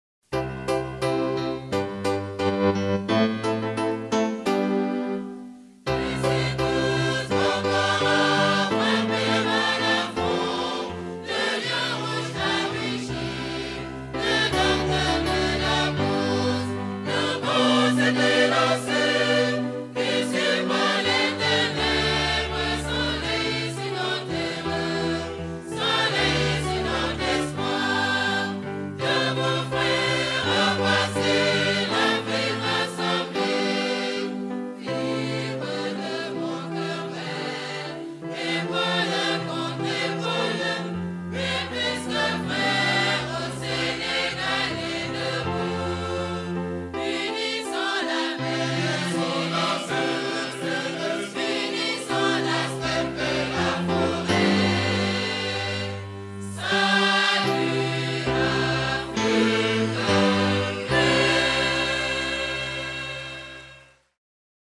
торжественную мелодию
со словами